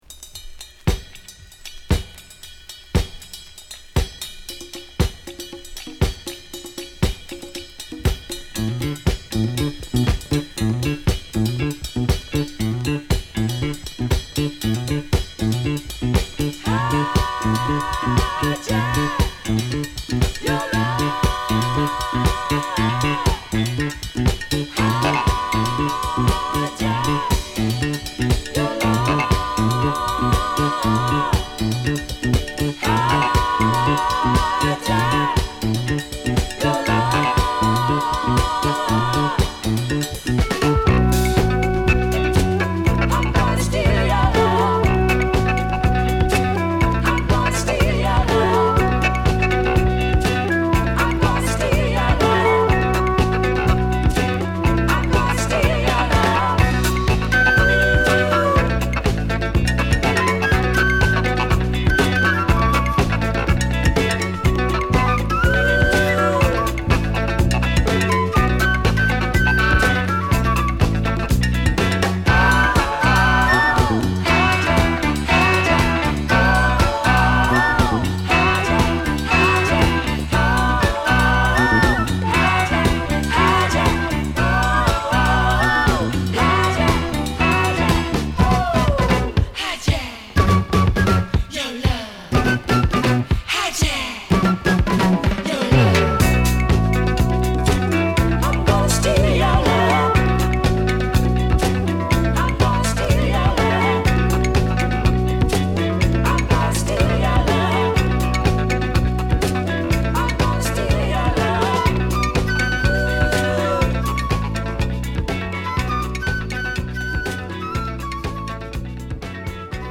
N.Y.ブルックリン出身のフルート奏者
小気味良いカウベルにパーカッション、キックドラム、お馴染みのベースリフ〜女性コーラスがジワジワと絡まるクラシック
フルートを絡めたクールなヴァージョンです！
ご機嫌なベースにエキゾチックなフルートが絡む